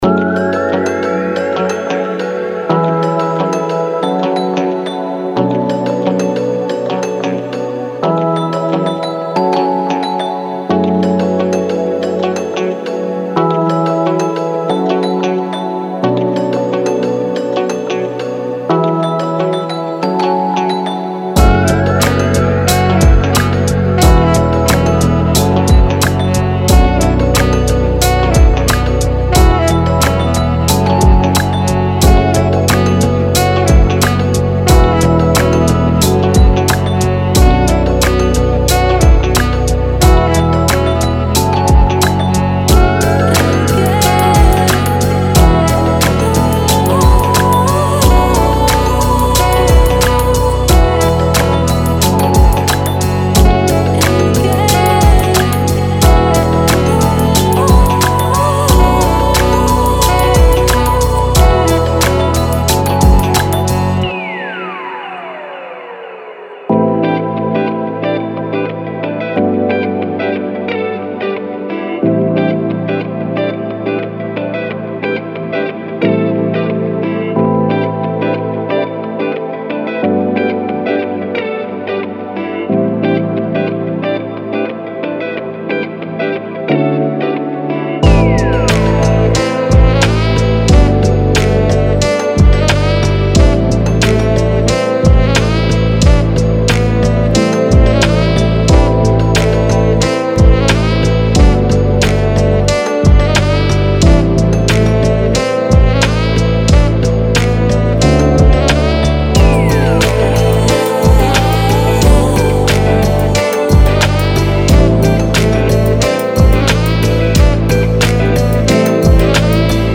Downtempo , Future Bass , House , Soul